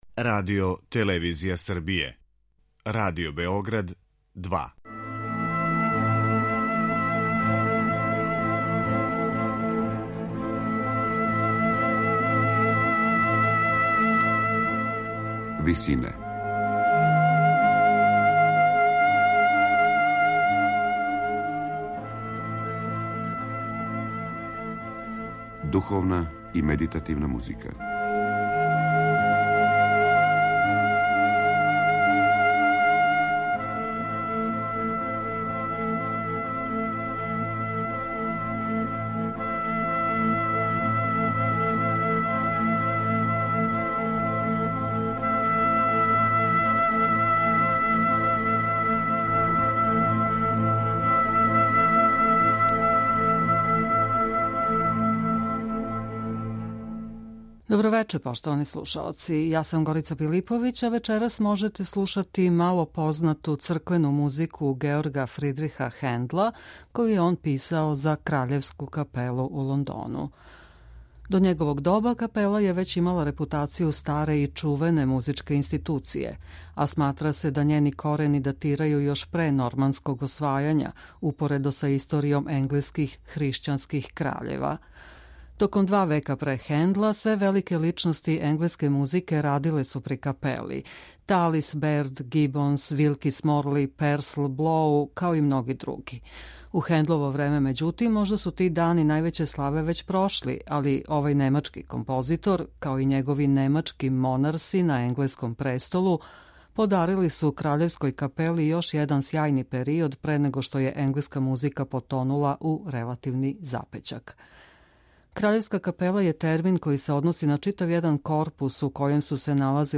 У вечерашњој емисији Висине од 19.05 можете слушати мало познату црквену музику Георга Фридриха Хендла, коју је он писао за Краљевску капелу у Лондону.